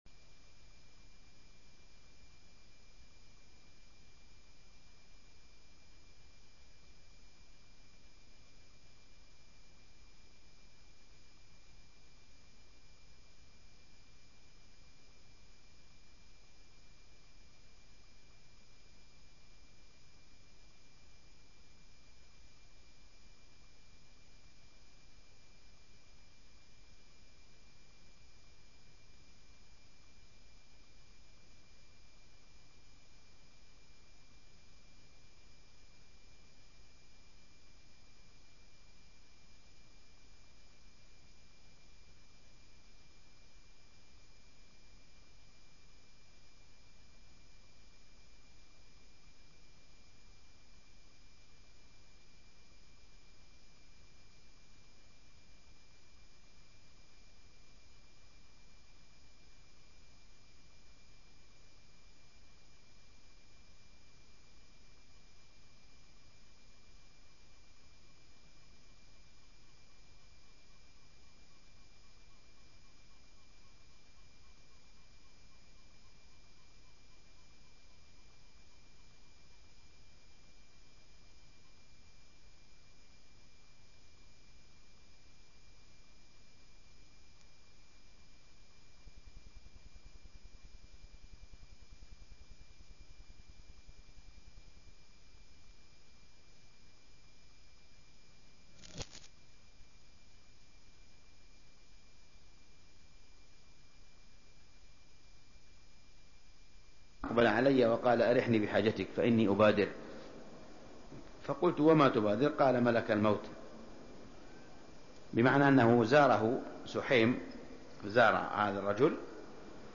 الدروس | موقع الشيخ صالح بن حميد